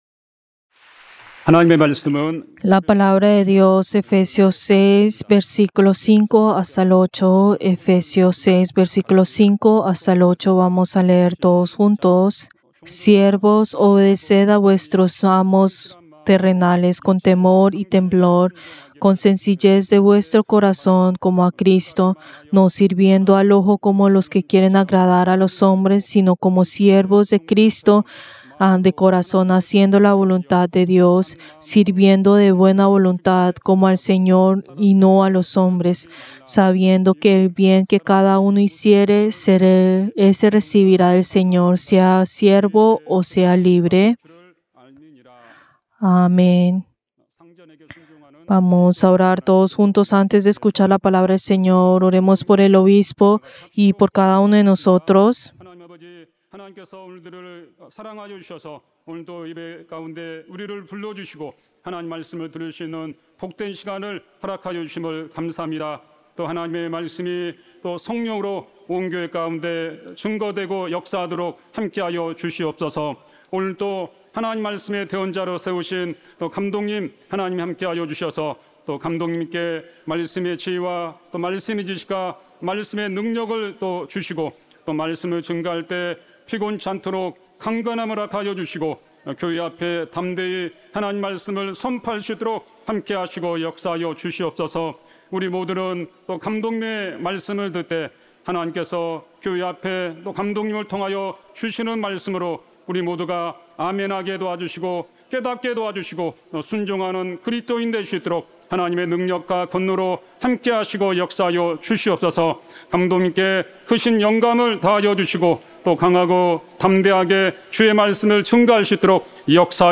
Servicio del Día del Señor del 25 de mayo del 2025